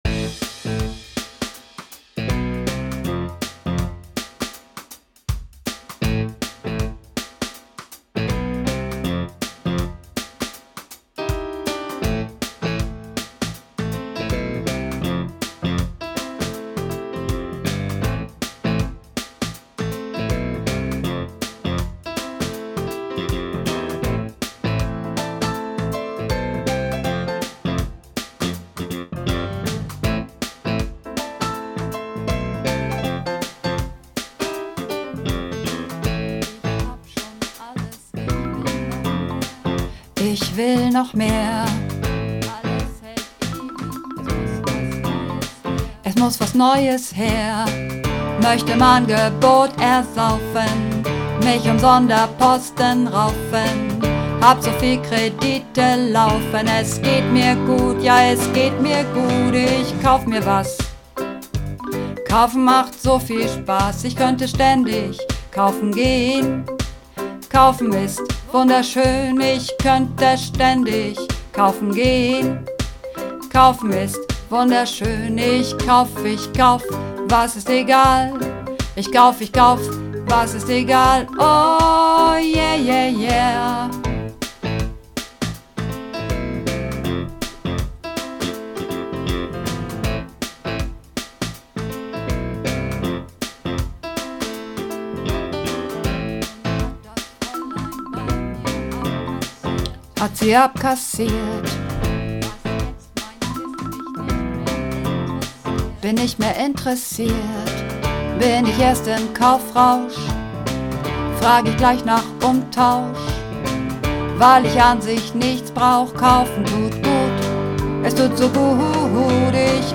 Übungsaufnahmen - Kaufen
Kaufen (Bass - lang)